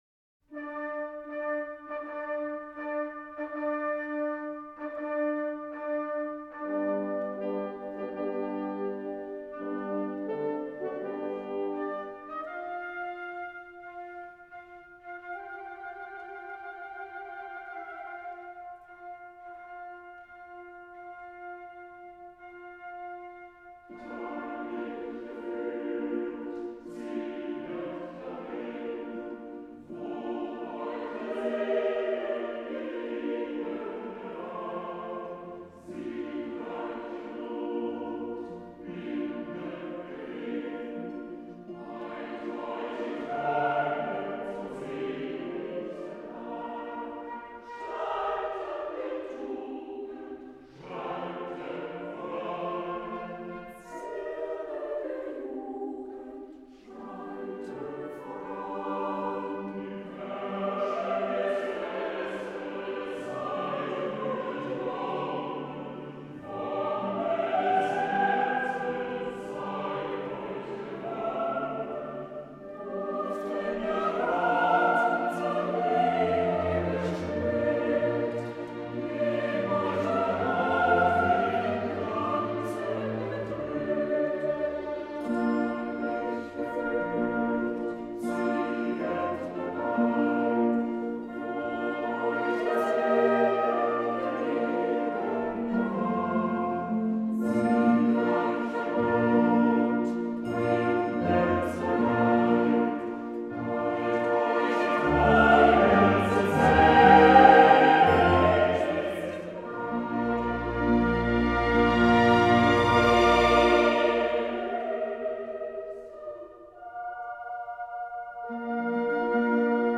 ウィーン･フィルハーモニー管弦楽団　指揮：ピエール･モントゥー
ベルリン・フィルハーモニー八重奏団　指揮：各奏者が担当
第1番はブラームス27才の1860年に完成された曲で、全体に青春の息吹を感じさせる若々しい気分に満ちた作品に仕上がっており、その第2楽章はこの作品の中心となっている。